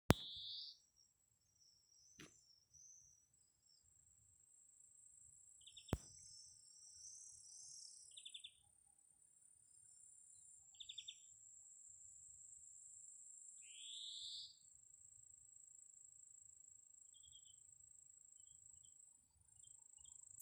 Birds -> Finches ->
Greenfinch, Chloris chloris
StatusSinging male in breeding season